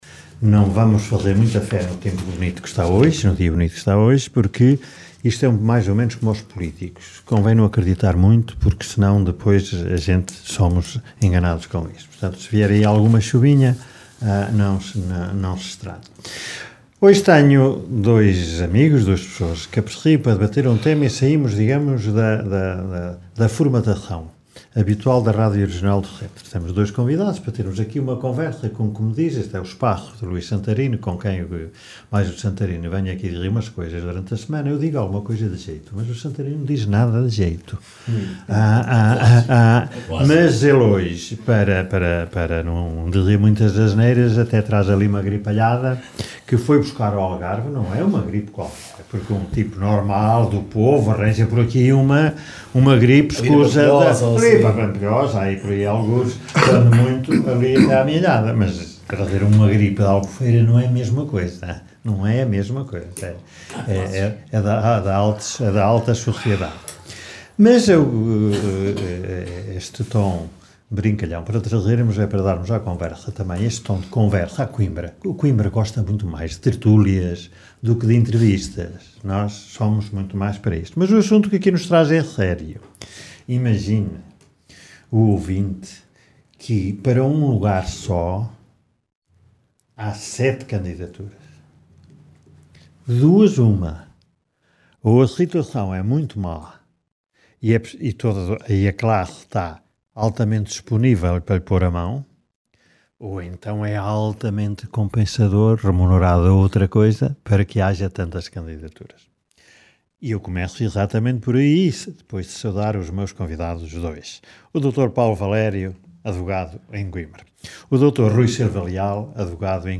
Debate com dois dos candidatos a Bastonário da Ordem dos Advogados